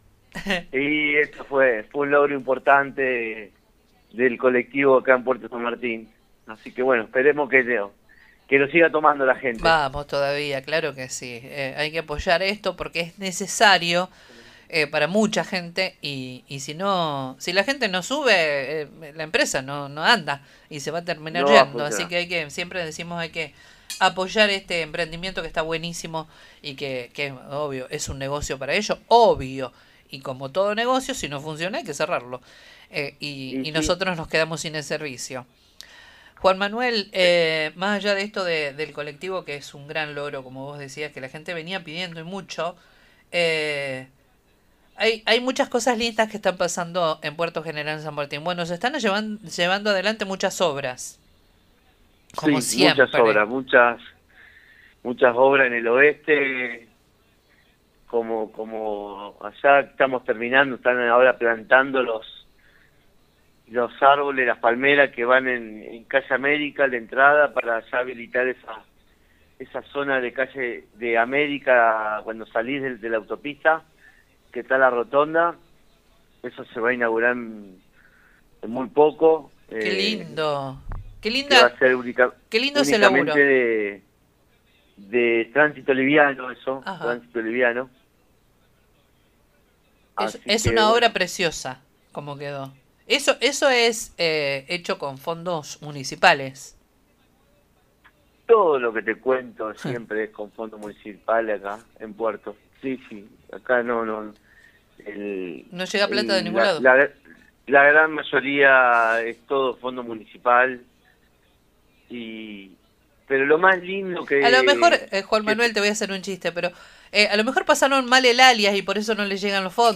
El concejal Juan Manuel De Grandis dialogó con el programa Con Voz de Radio 102.9 y se refirió a las principales obras que se ejecutan en Puerto General San Martín, además de abordar las inquietudes más frecuentes de los vecinos.